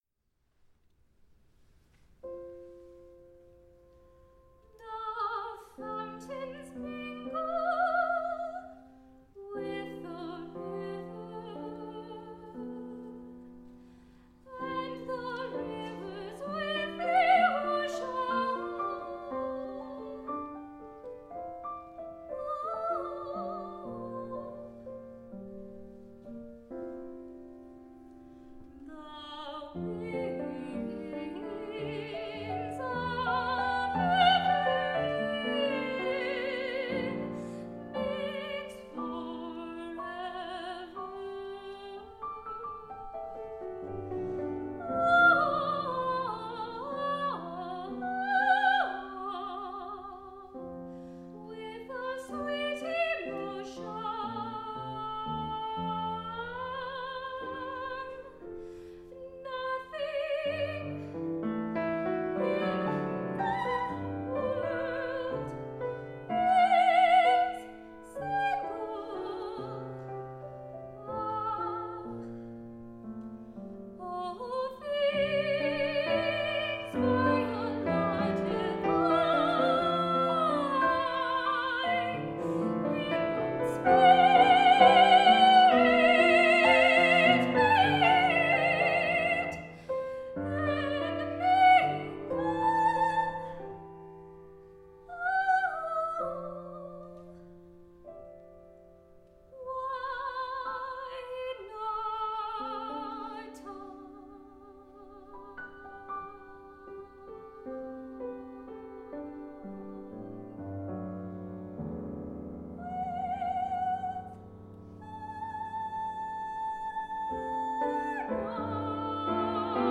Soprano and piano
The fragment transforms throughout the piece and grows segments that bloom like flowers, but the music retains a strong sense of familiarity.